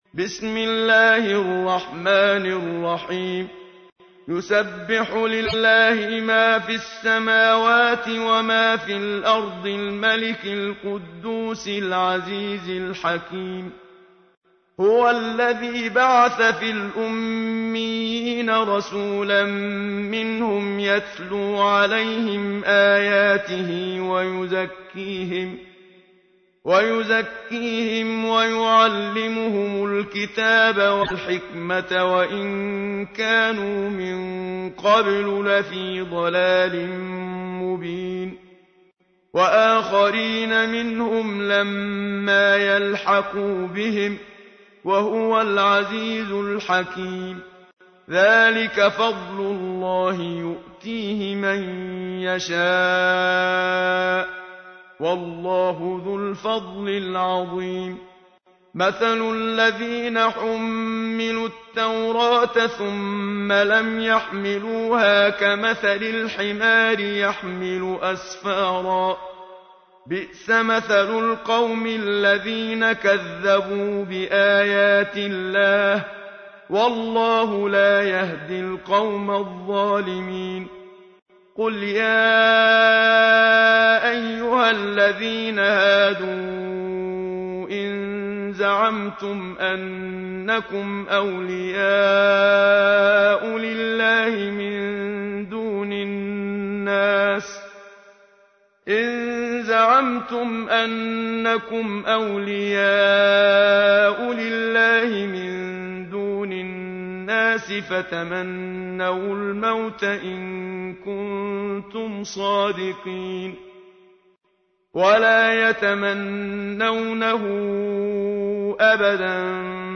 تحميل : 62. سورة الجمعة / القارئ محمد صديق المنشاوي / القرآن الكريم / موقع يا حسين